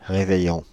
Ääntäminen
Synonyymit la veille Ääntäminen Paris: IPA: [ʁe.vɛ.jɔ̃] France (Île-de-France): IPA: /ʁe.vɛ.jɔ̃/ Haettu sana löytyi näillä lähdekielillä: ranska Käännös Substantiivit 1. ρεβεγιόν (revegión) Suku: m .